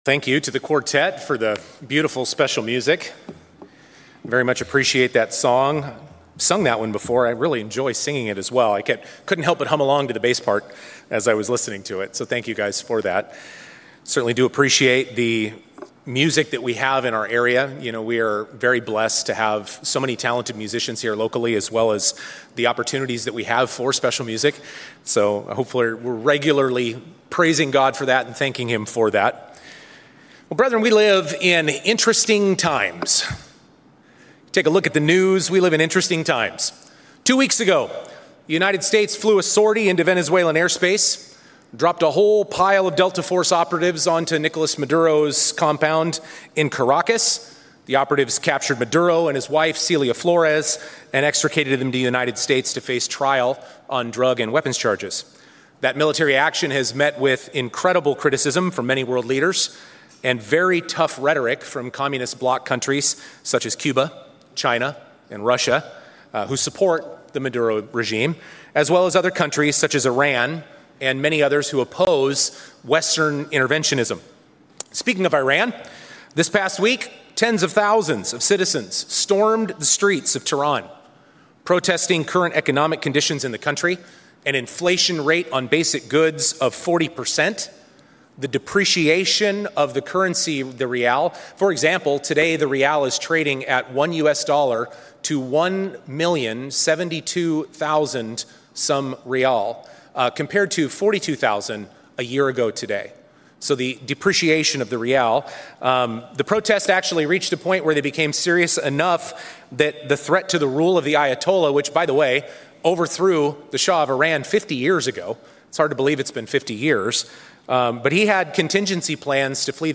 In this sermon, we step back from the headlines to examine what Scripture actually says about the conditions leading up to the return of Jesus Christ. Using Christ’s Olivet Prophecy and key passages from Daniel, Revelation, Hosea, and the Gospels, we examine seven prophetic markers that must be in place before Christ’s return — some already fulfilled, others still developing.